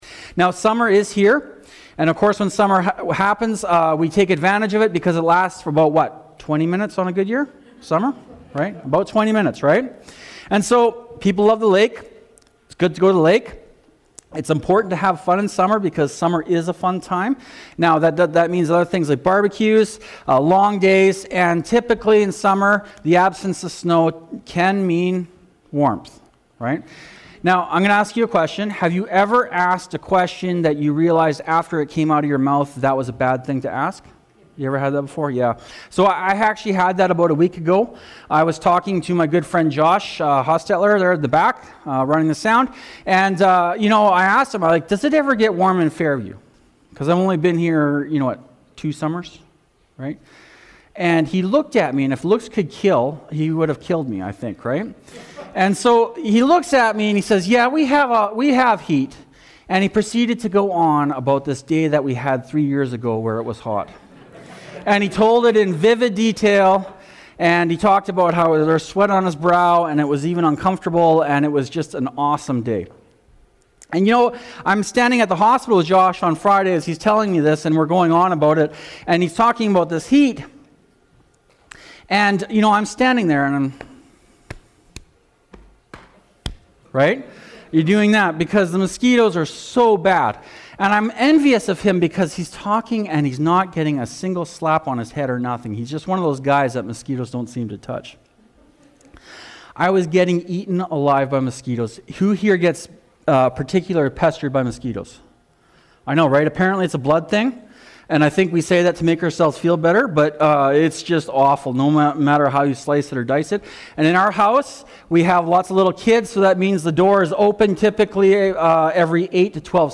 June-28-sermon-audio.mp3